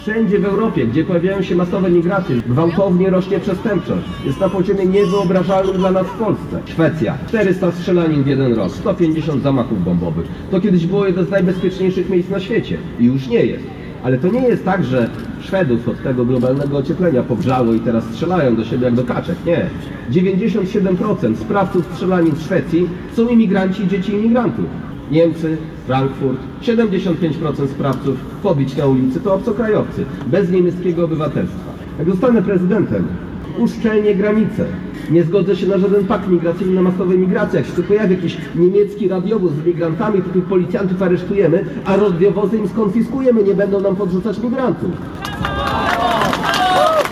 Spotkanie odbyło się na Rynku Staromiejskim i przyciągnęło tłumy mieszkańców, którzy przyszli wysłuchać jego postulatów oraz krytyki obecnych władz.